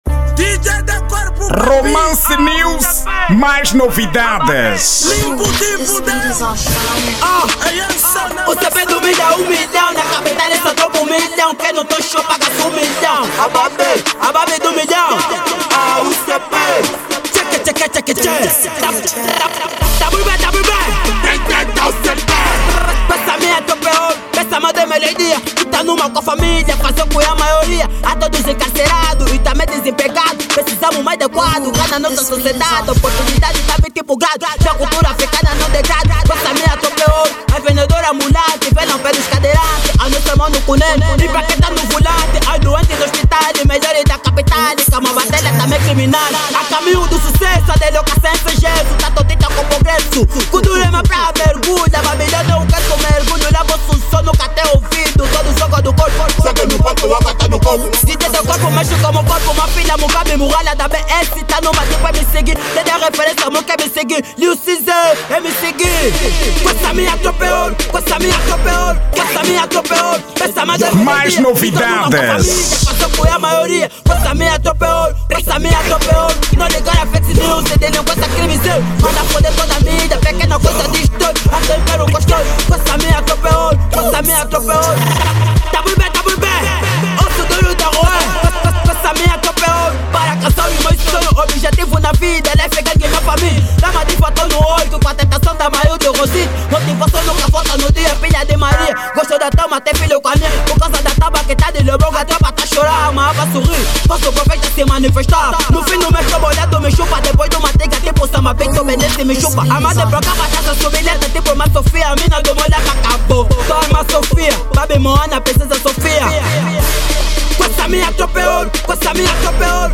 Estilo: Kuduro